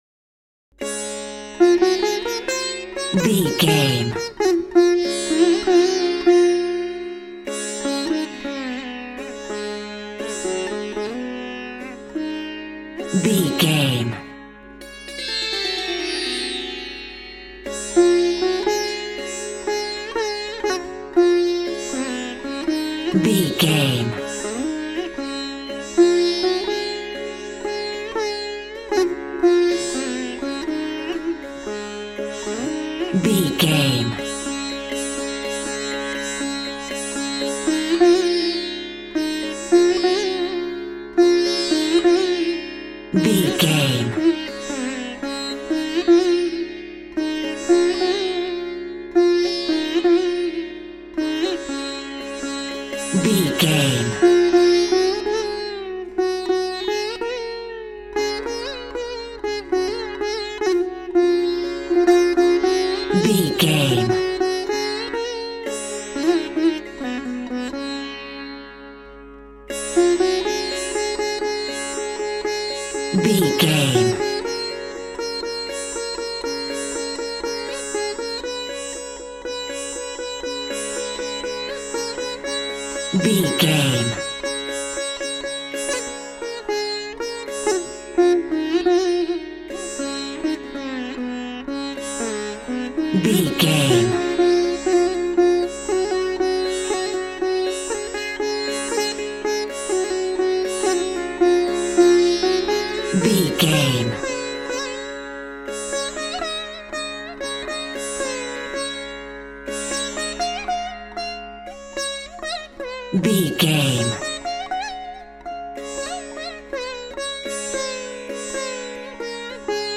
Mixolydian
D♭